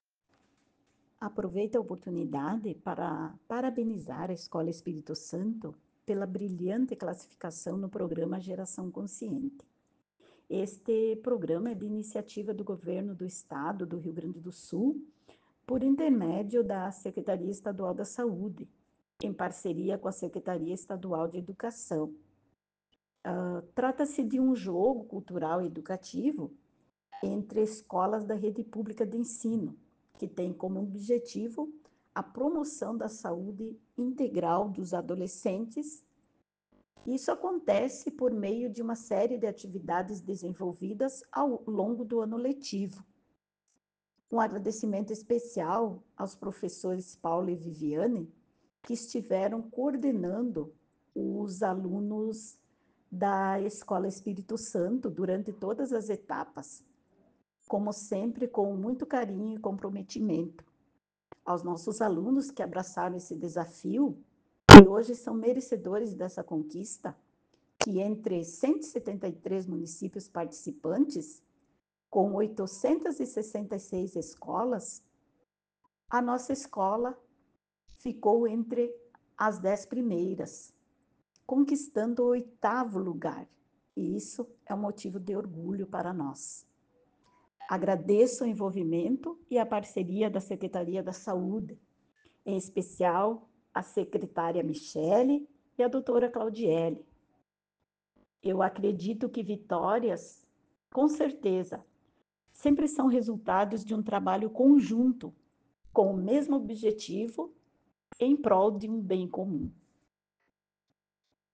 A Secretária Municipal de Educação, Cultura e Desporto, Jorgina de Quadros, falou sobre essa premiação da escola.